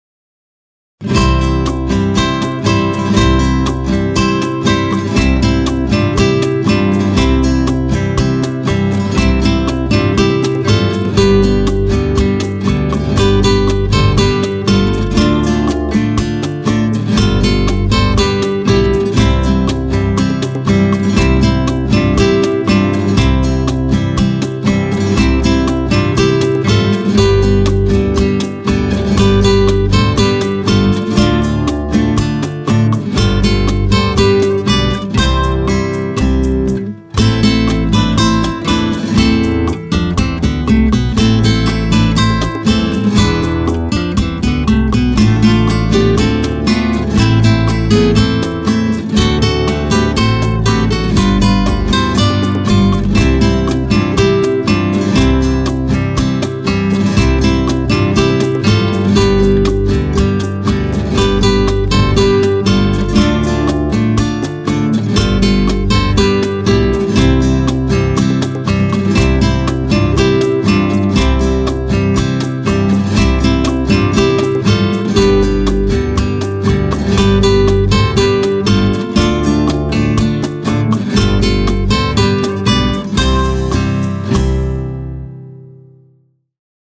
Gitarre, akustische Gitarre